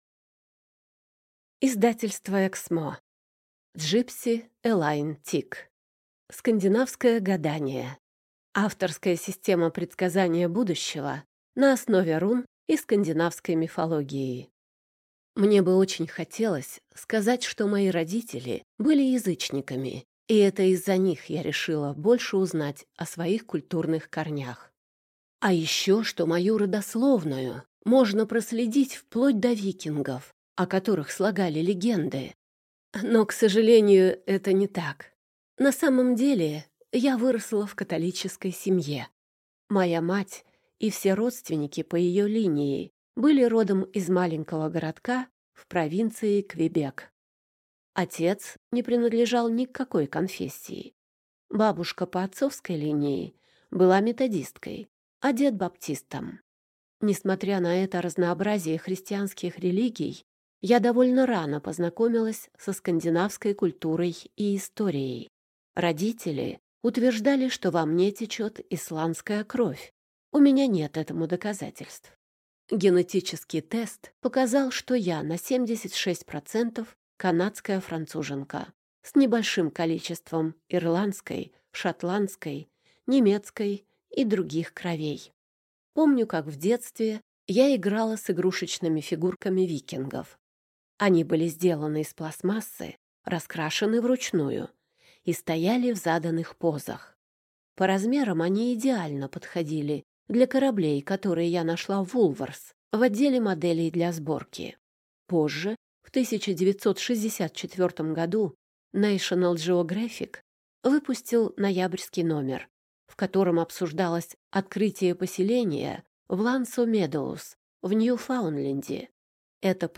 Аудиокнига Скандинавское гадание. Авторская система предсказания будущего на основе рун и скандинавской мифологии | Библиотека аудиокниг